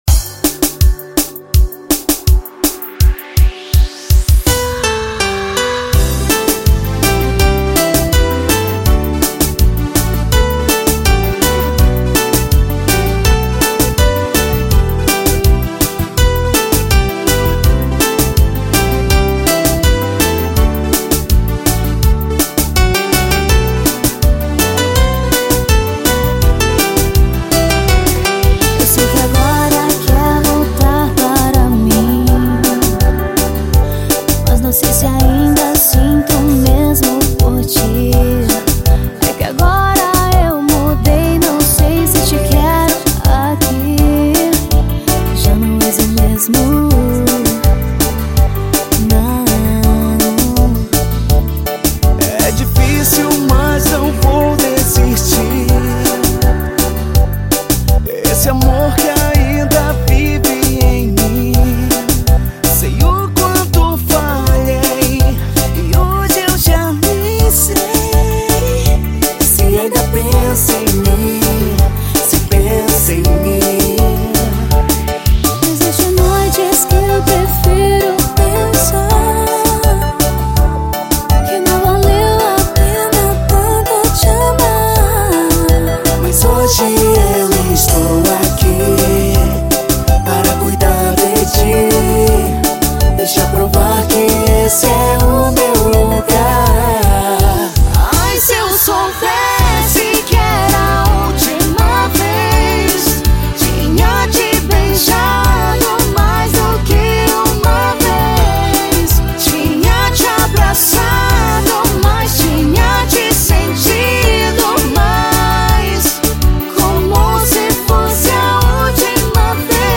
EstiloTecnobrega